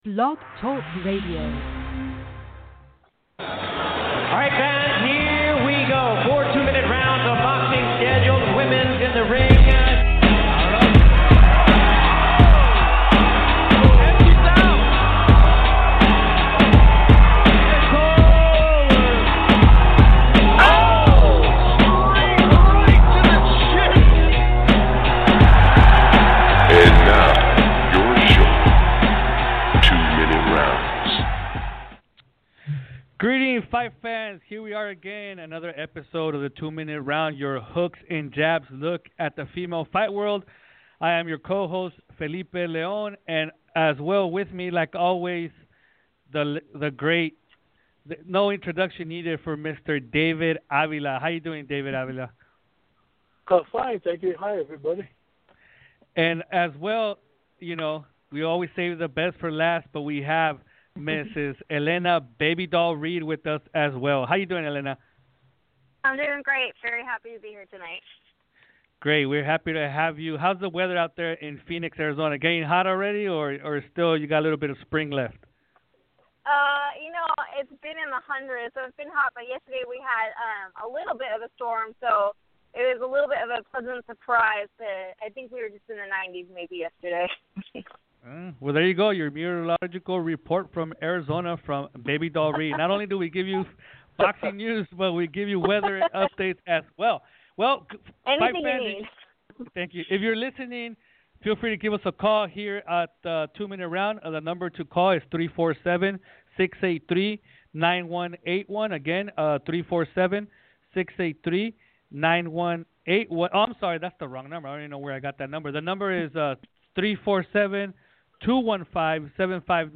A bi-weekly podcast focused on all the news, notes, results, upcoming fights and everything in between in the wide world of female professional boxing. This week we will have the past weeek's fight results and upcoming calendar, female fight chatter and an exclusive interview...